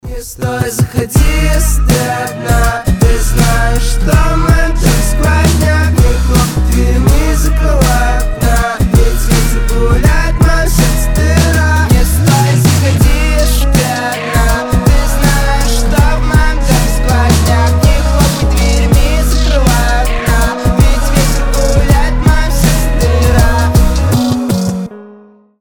• Качество: 320, Stereo
гитара
дуэт